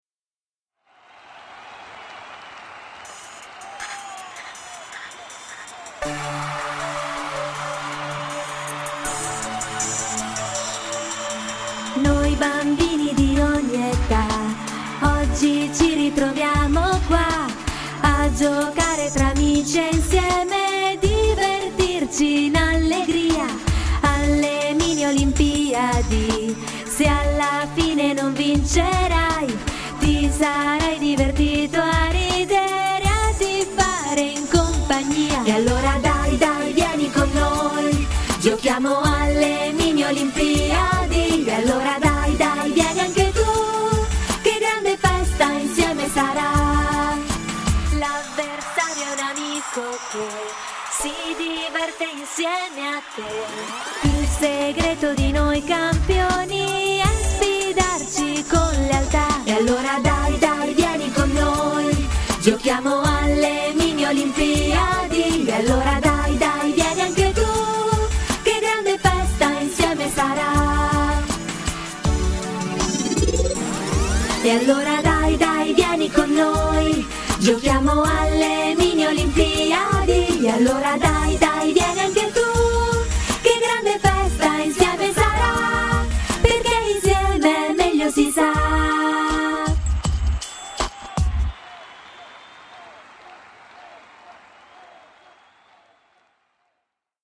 In anteprima vorrei farvi ascoltare l’inno della manifestazione sportiva dedicata ai più piccoli: le “Mini Olimpiadi“!
E’ la prima volta che creo un brano per bambini.